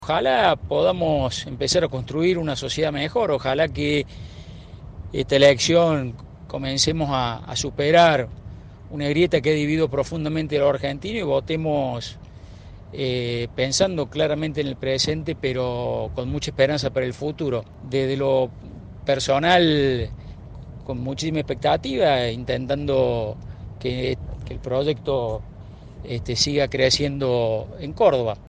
Audio. Juan Pablo Quinteros votó en Córdoba y habló de "salir de la grieta"